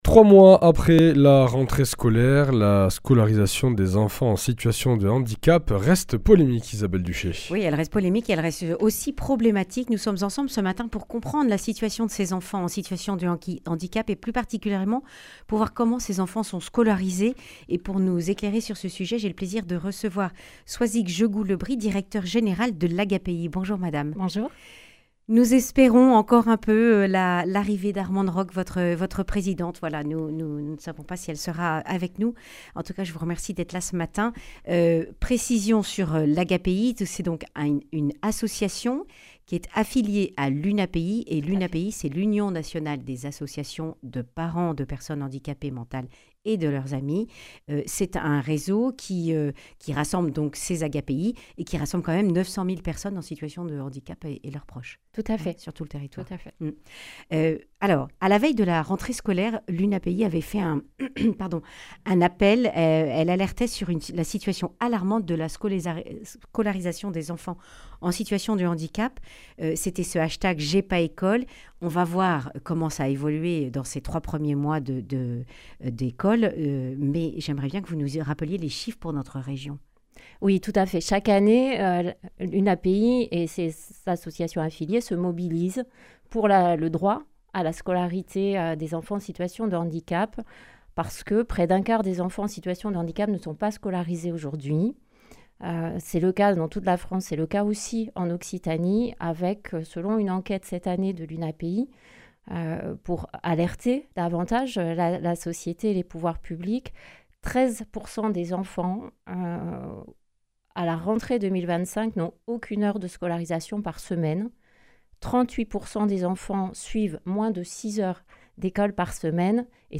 Accueil \ Emissions \ Information \ Régionale \ Le grand entretien \ Trois semaines avant Noël, quelles avancées pour la scolarisation des (…)